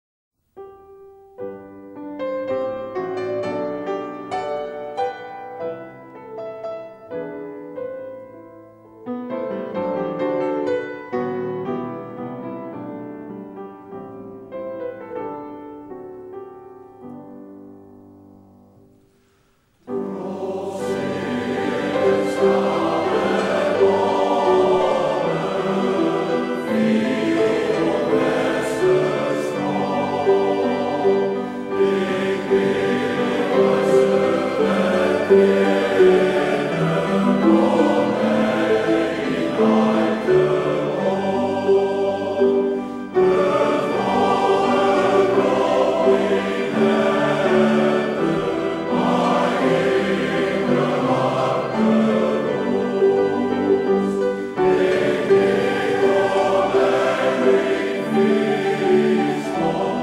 sopraan
bariton
orgel
marimba
basgitaar
Ad hoc-orkest.